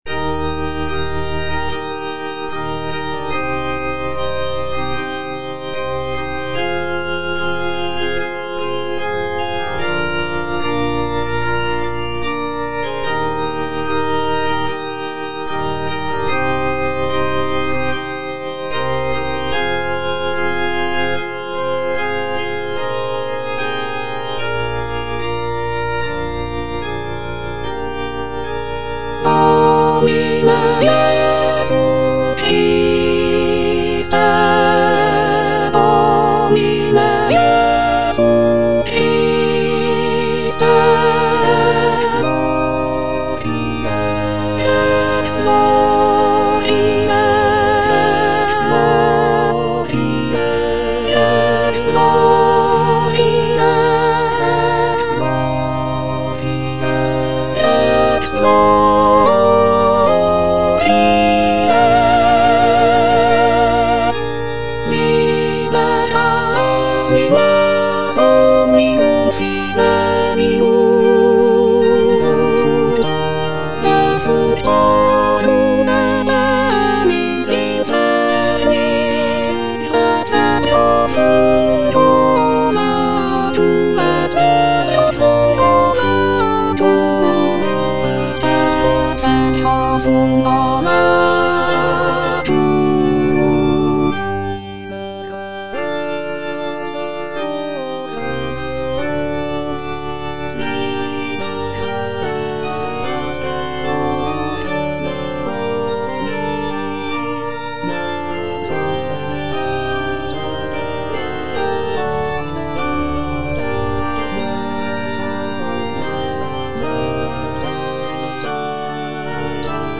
Soprano (Emphasised voice and other voices) Ads stop
is a sacred choral work rooted in his Christian faith.